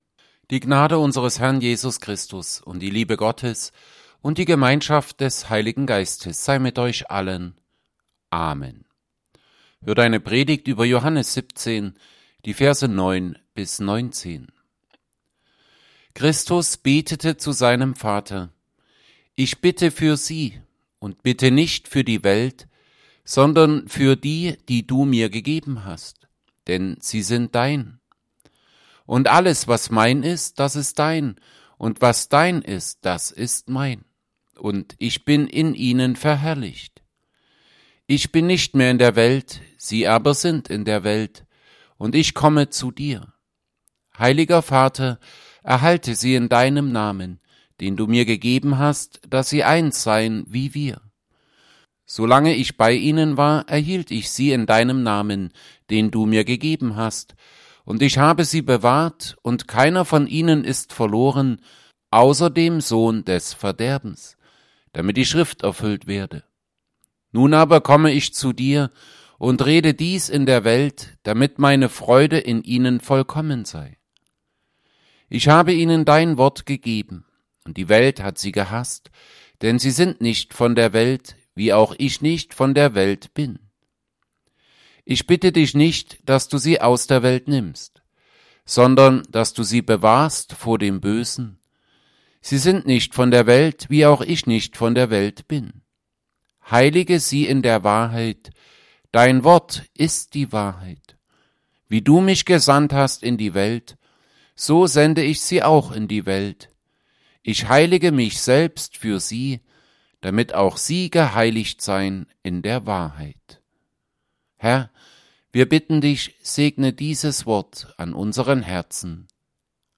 Predigt_zu_Johannes_17_9b19.mp3